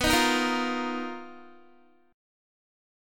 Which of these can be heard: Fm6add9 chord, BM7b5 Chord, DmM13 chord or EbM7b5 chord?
BM7b5 Chord